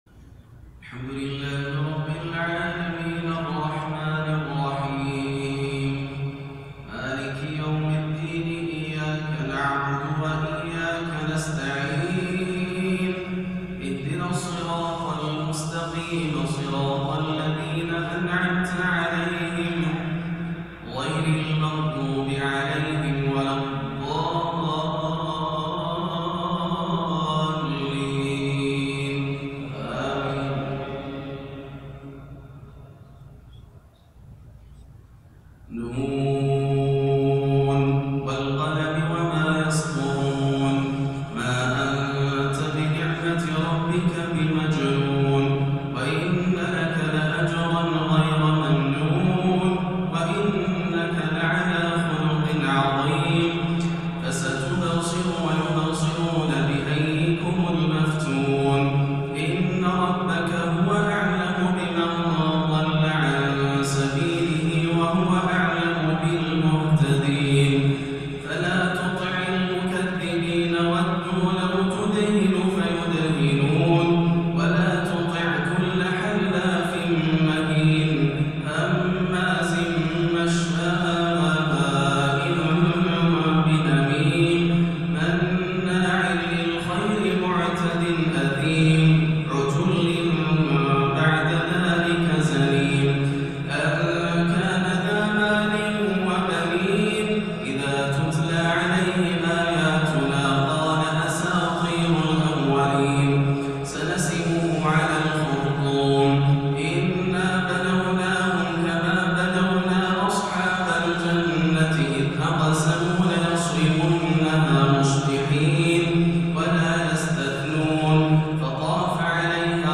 مغرب الأثنين 5-1-1439هـ سورة القلم > عام 1439 > الفروض - تلاوات ياسر الدوسري